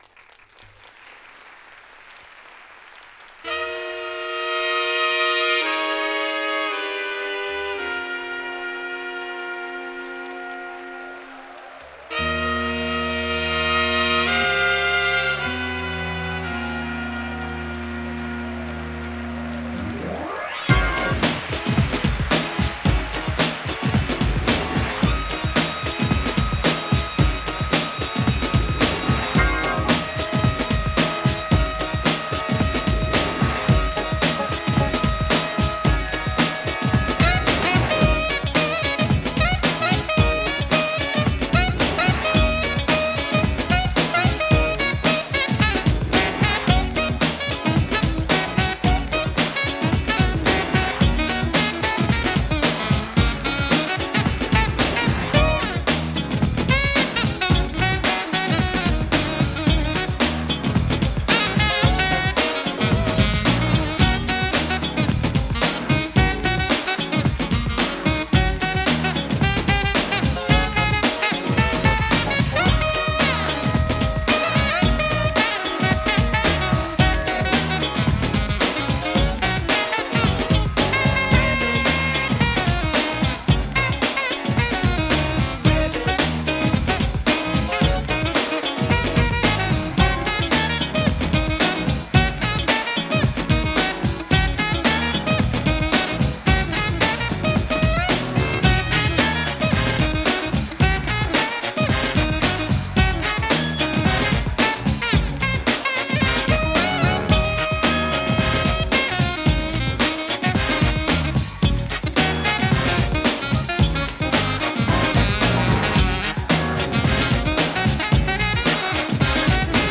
重 弾力 ファンキー パーティー HIP HOP バップ ジャズ
重く弾力のあるトラックにブロウするSAXがカッコいいパーティー・チューン！